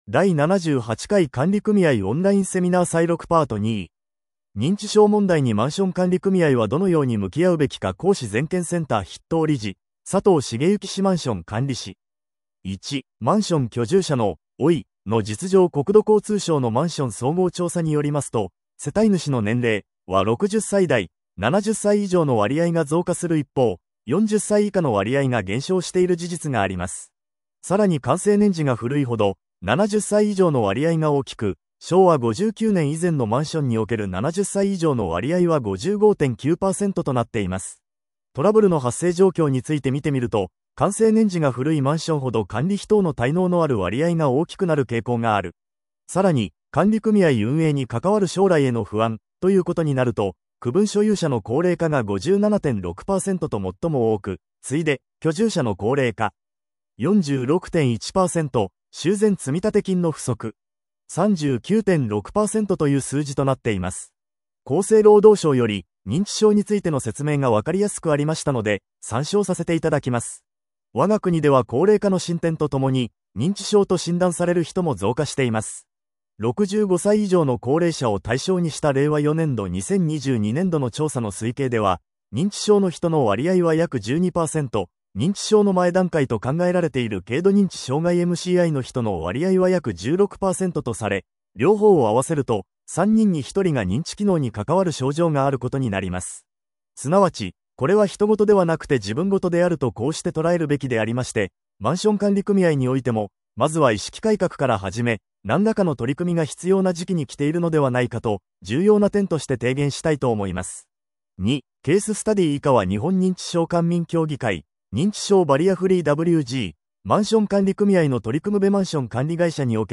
第78回管理組合オンライン・セミナー採録Part.2